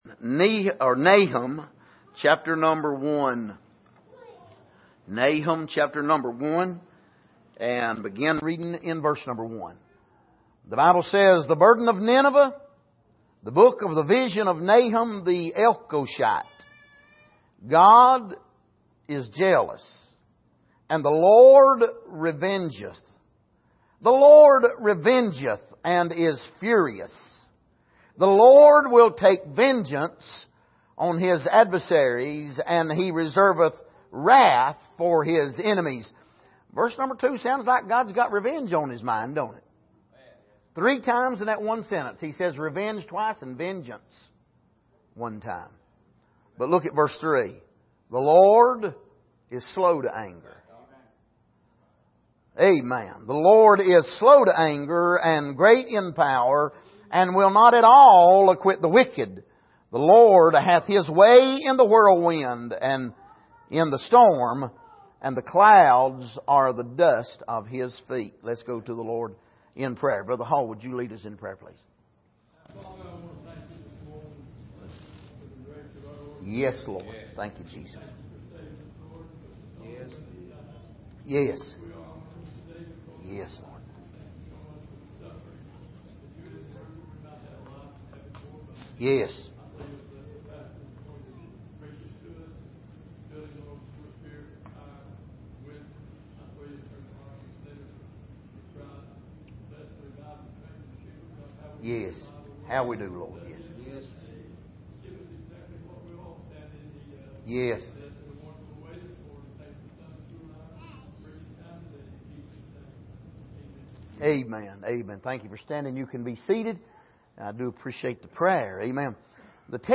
Passage: Nahum 1:1-3 Service: Sunday Morning